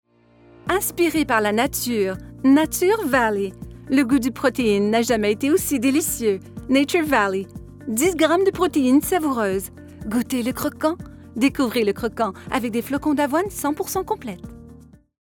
Promotions
-Neumann TLM 103 Microphone
-Professional sound isolation recording booth